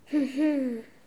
hum-hibou_01.wav